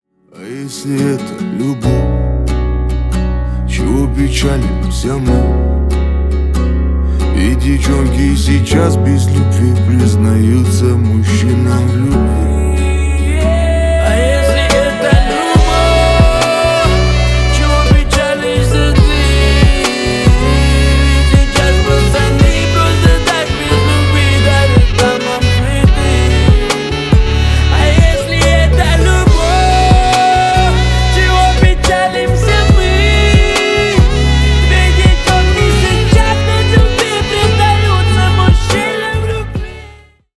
Поп Музыка
грустные